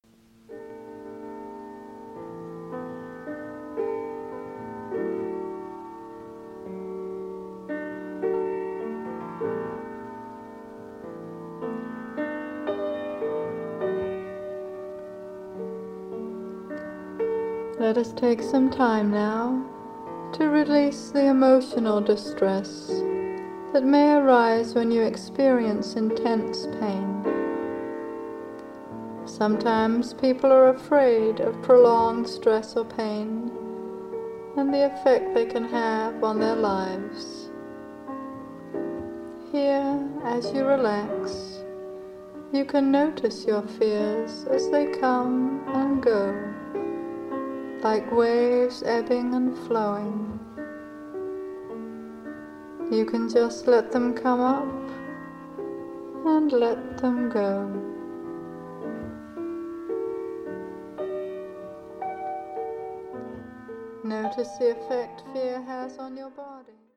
Seasons for Healing: Winter (Guided Meditation)
Piano and Synthesizer
Flute